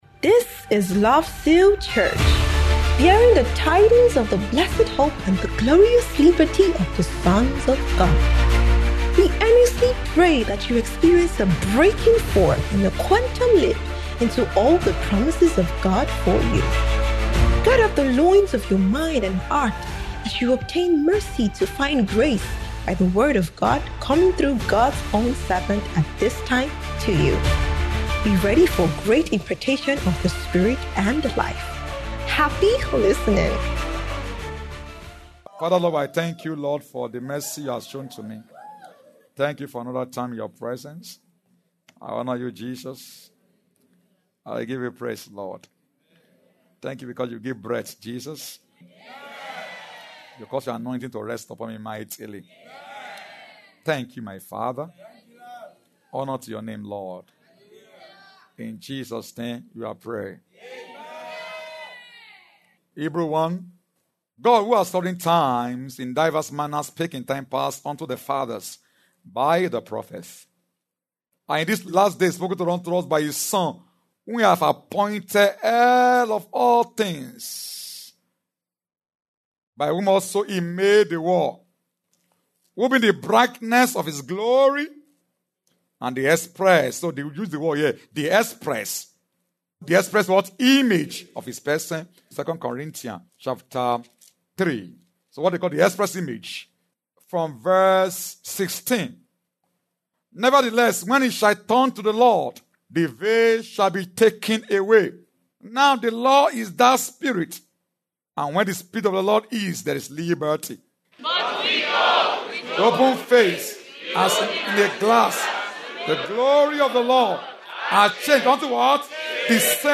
SUNDAY APOSTOLIC BREAKTHROUGH IMPARTATION SERVICE